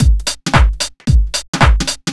All these loops were created at loopasonic and are all original and copyright free.
GARAGE 112 (185Kb)
garage1.wav